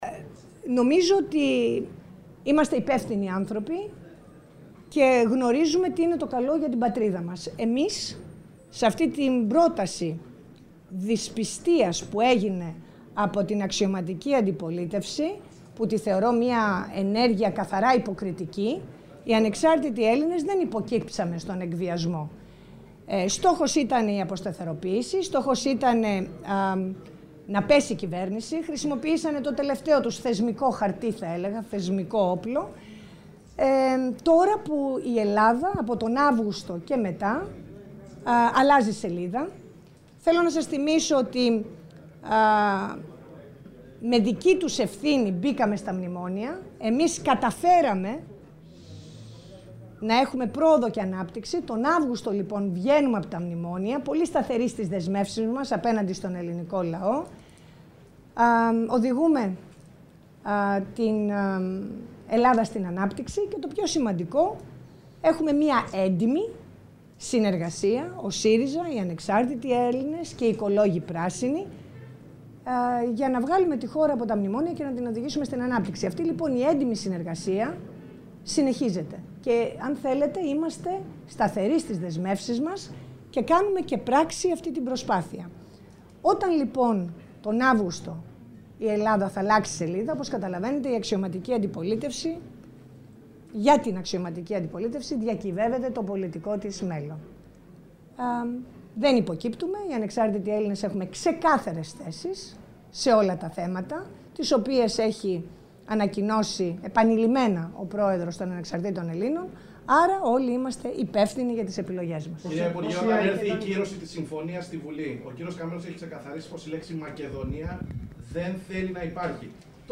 Η κ. Κουντουρά μιλώντας στους δημοσιογράφους κατά την τελετή των εγκαινίων των γραφείων των Ανεξαρτήτων Ελλήνων στα Χανιά και απαντώντας σε ερώτηση για τις αποχωρήσεις στελεχών του κόμματος με αφορμή την ονομασία της πΓΔΜ, τόνισε ότι ο κάθε ένας κάνει τις επιλογές του και επεσήμανε:
Κουντουρά-Πολιτικό.mp3